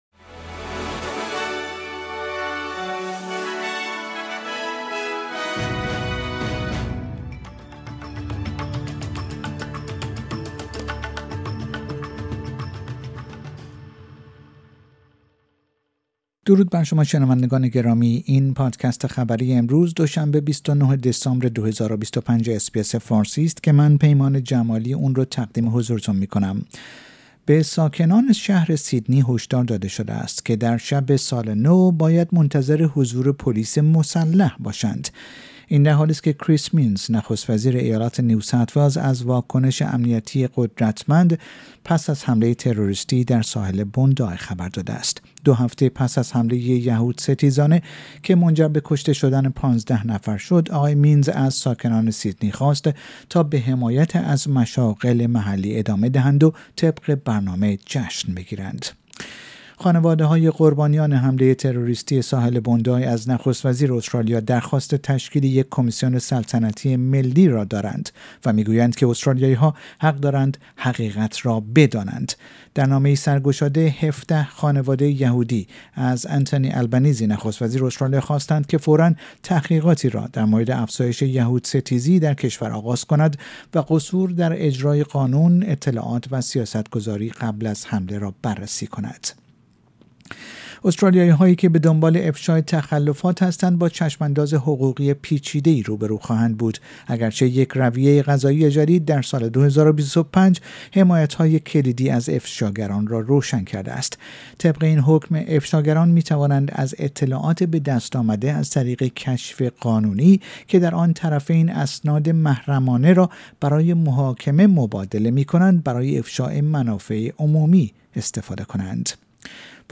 در این پادکست خبری مهمترین اخبار روز دوشنبه ۲۹ دسامبر ارائه شده است.